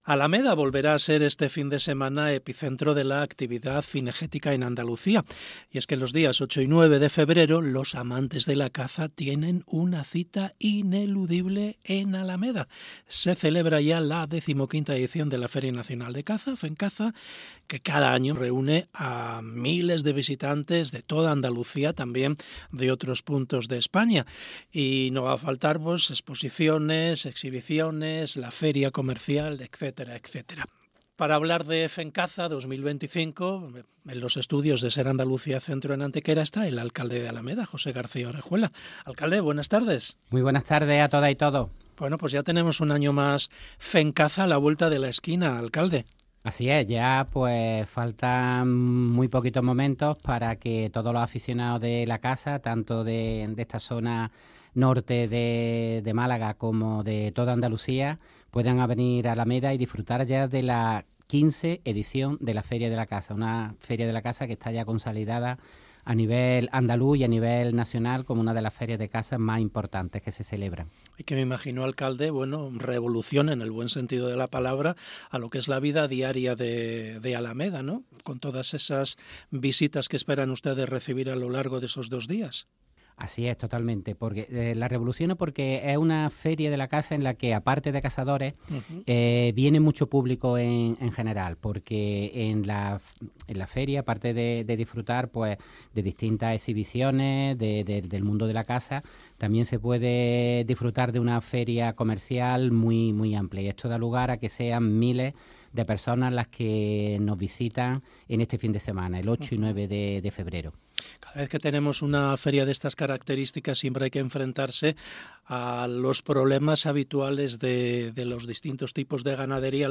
Entrevista José García Orejuela. FENCAZA 2025 - Andalucía Centro
Se celebra la decimoquinta edición de la Feria Nacional de Caza (Fencaza), que cada año reúne a miles de visitantes de toda Andalucía y España. Habrá exposiciones, exhibiciones y una feria comercial José García Orejuela, alcalde de Alameda, ha pasado por el programa Hoy por Hoy SER Andalucía Centro.